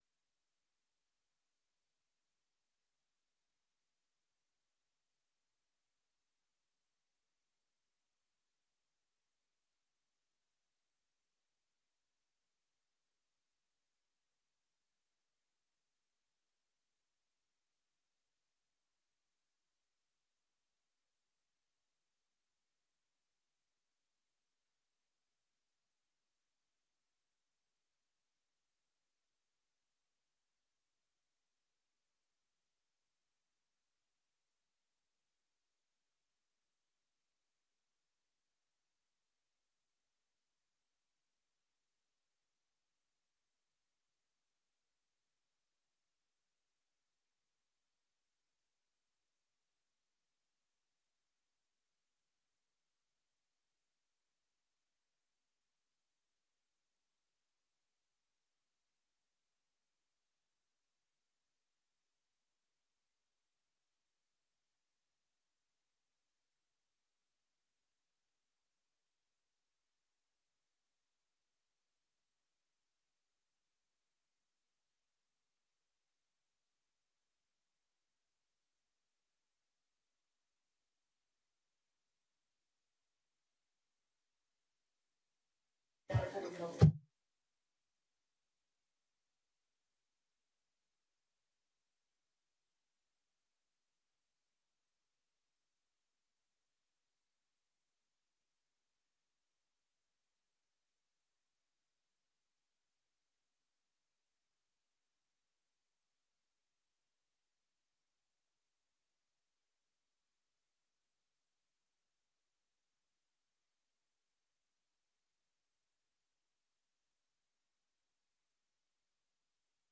Locatie: Raadzaal